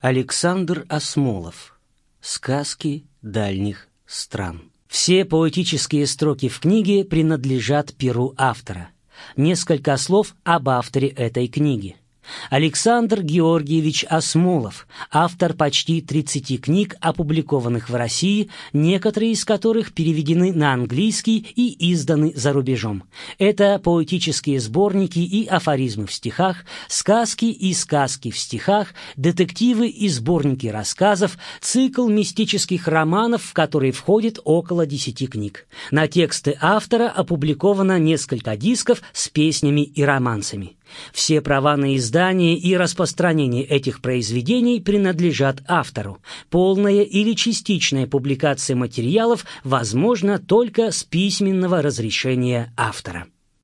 Аудиокнига Сказки Давних времен | Библиотека аудиокниг